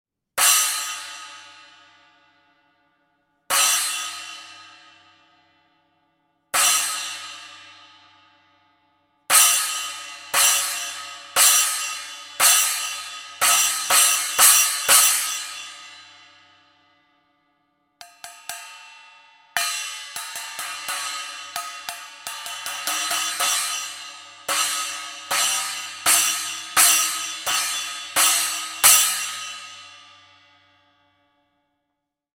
8″ Armor Holey Splash Cymbals (Approx 160 grams):
8__Armor_Holey-Splash.mp3